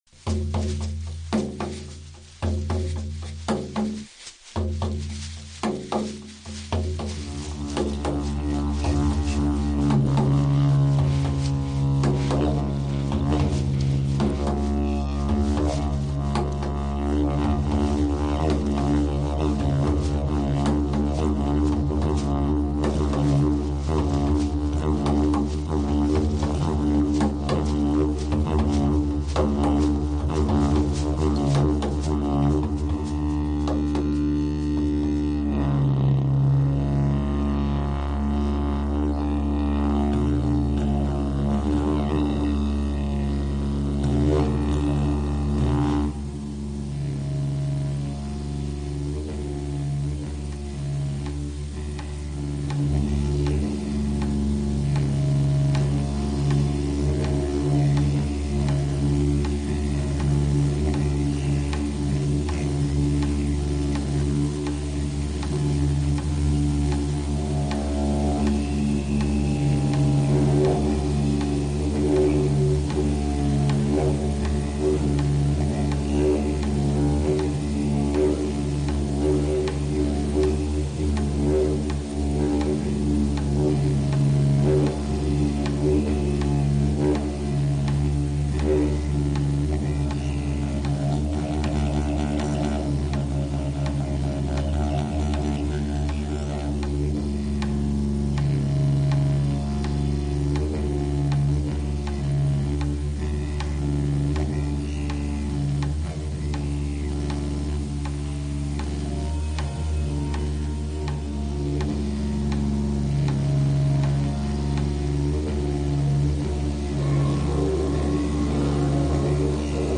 Zvuková lázeň
Pří této terapii využíváme léčivých vibrací prastarých hudebních duchovních nástrojů, jako jsou rezonanční lehátko, deštný sloup, sférická píšťala, didgeridoo, tibetské mísy, šamanský buben a další.
zvukova-lazen201.mp3